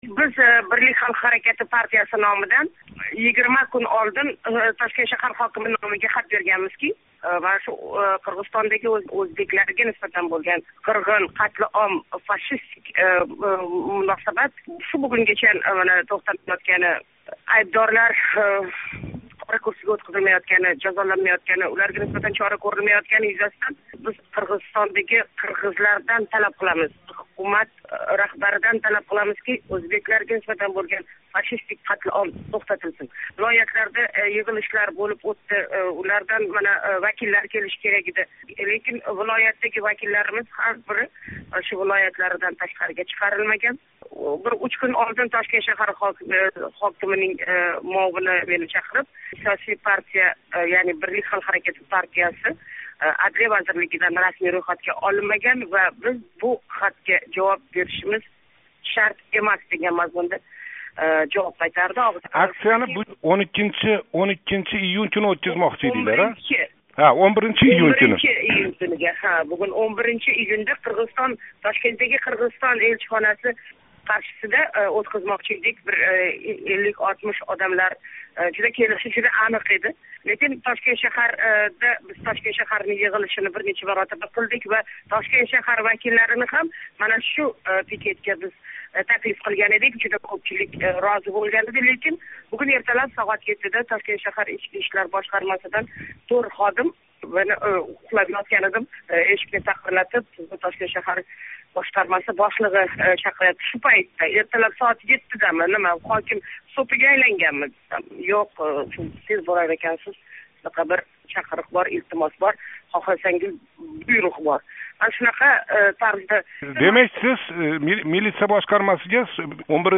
Васила Иноятова билан суҳбат